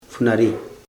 [fu.na.ri] noun plant three